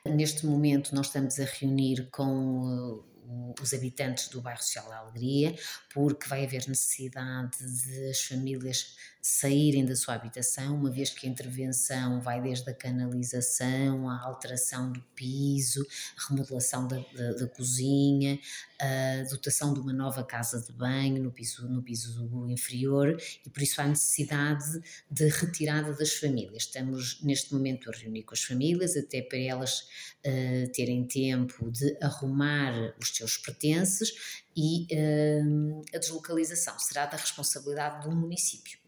Para já, a obra será faseada, e em coordenação com as famílias beneficiadas, como explica a vereadora, Susana Viana, da Área Social e Habitação do município macedense: